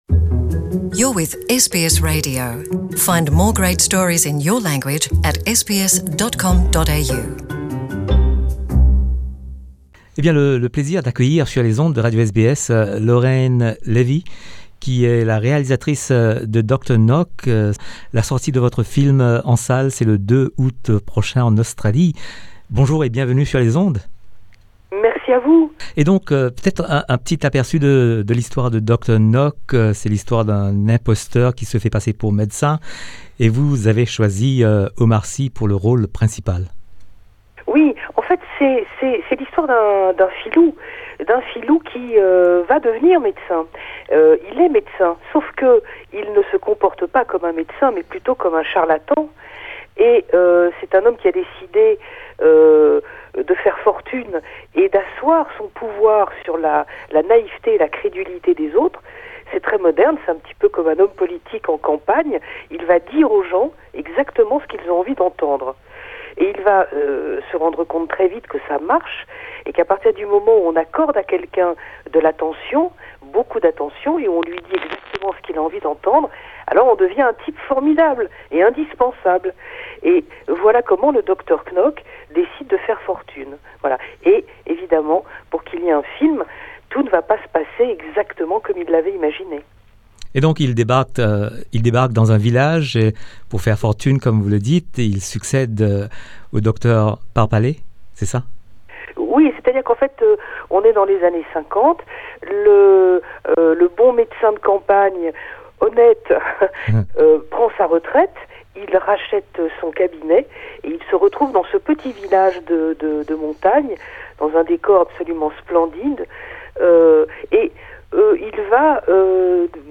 Rencontre avec Lorraine Lévy, réalisatrice de Dr Knock qui sort en salles en Australie le 2 aout prochain.